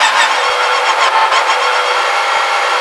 rr3-assets/files/.depot/audio/sfx/transmission_whine/tw_offmid.wav